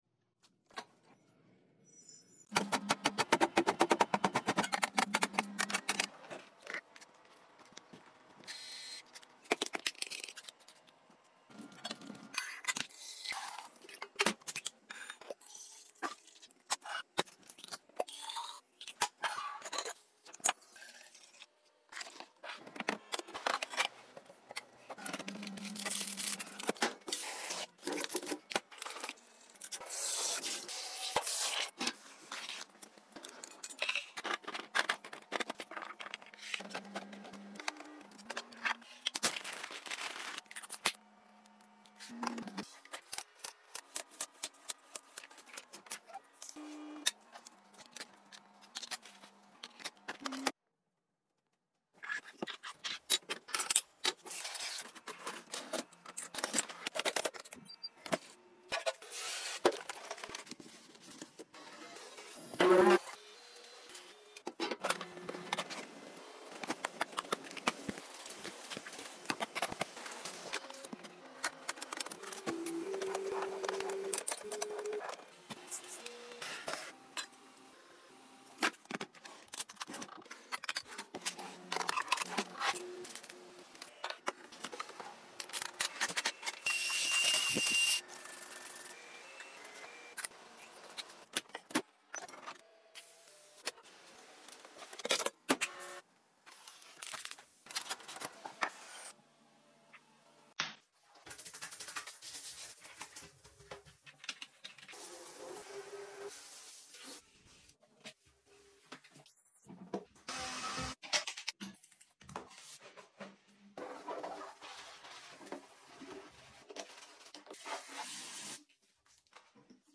Asmr restocks and cleaning 🧹 sound effects free download